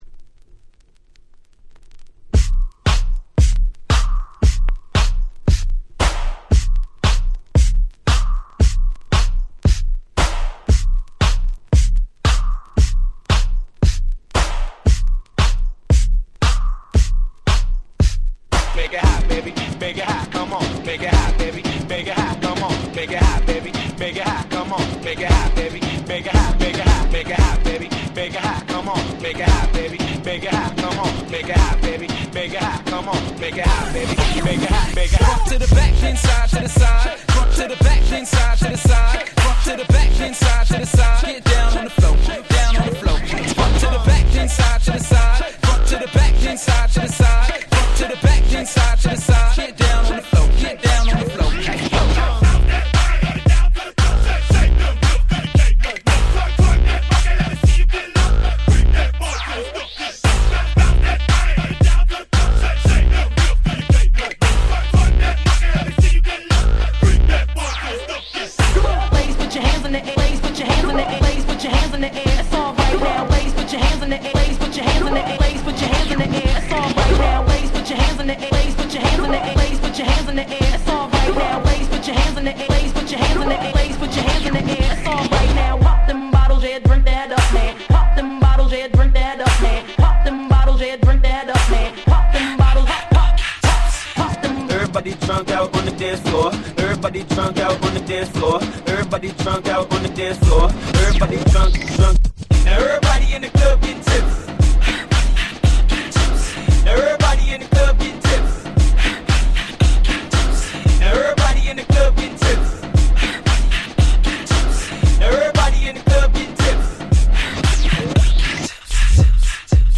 08' Smash Hit R&B !!
どのRemixもフロア映えする即戦力なRemixです！